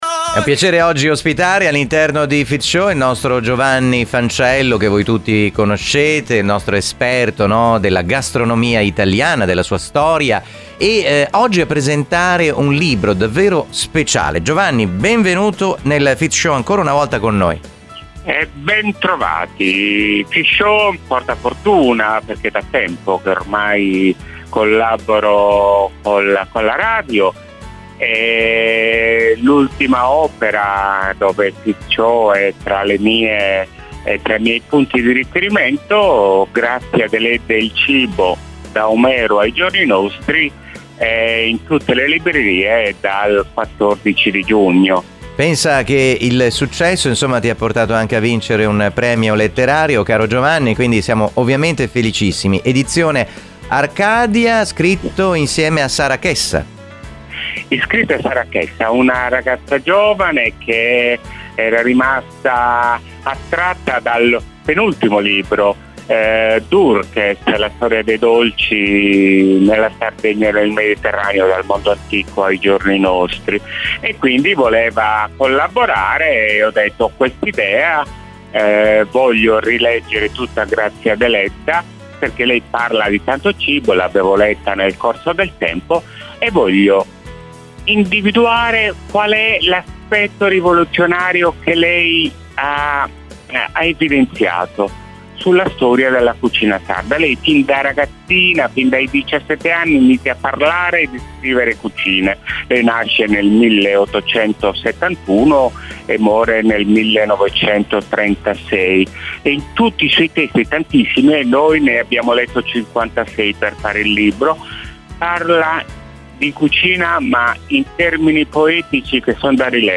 Un talk show con ospiti illustri e tanti personaggi, giornalisti, opinionisti ed esperti.